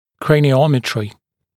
[ˌkreɪnɪ’ɔmɪtrɪ][ˌкрэйни’омитри]краниометрия, измерение черепа